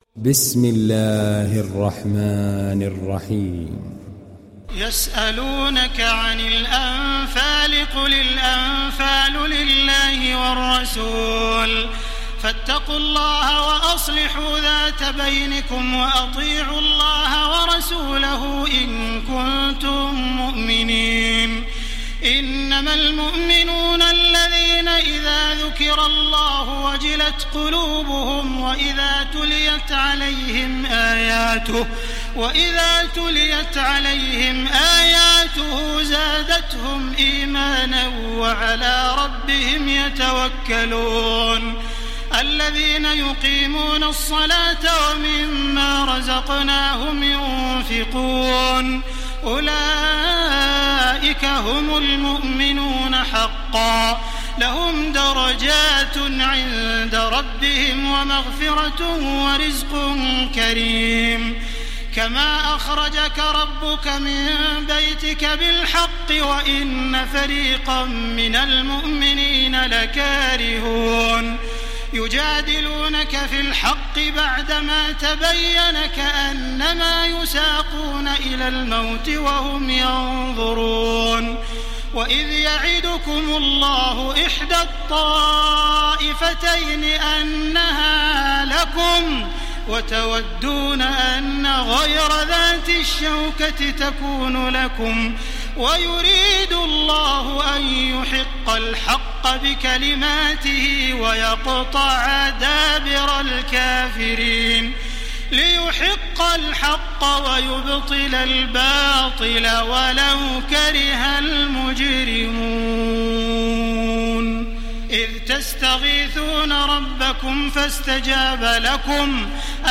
Télécharger Sourate Al Anfal Taraweeh Makkah 1430